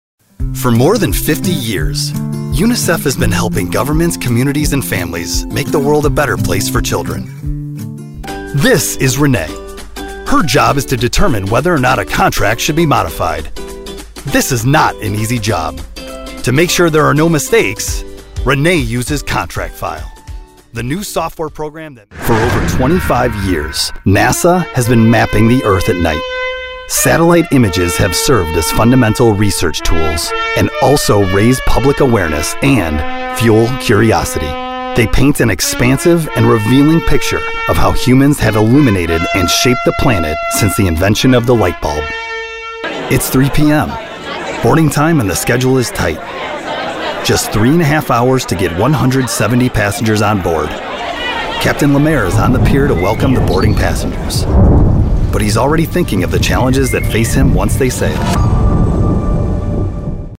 Deep, Powerful Voice! Pro Studio!
Narration Demo Reel
Midwest American, Southern American
Middle Aged
In need of a deep, powerful, husky voice?